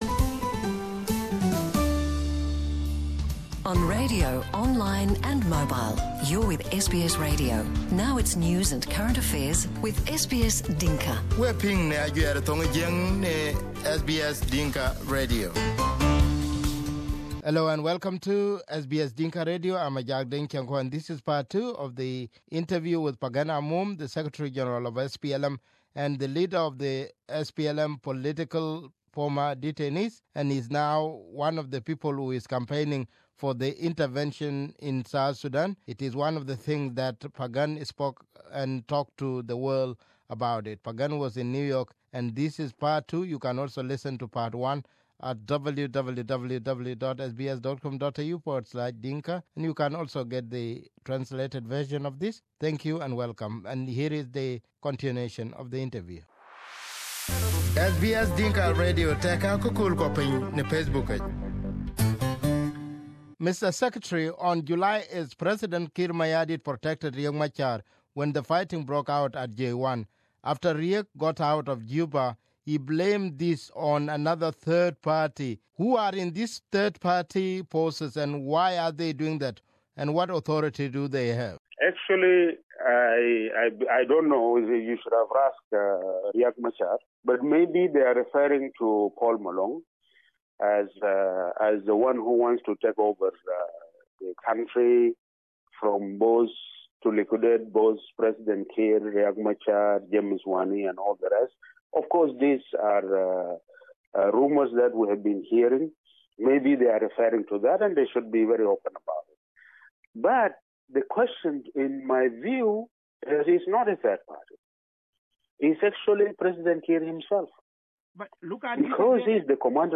This is part two of interview with SPLM secretary Pagan Amum. When he was in New York, Pagan explained why he is campaigning for the intervention.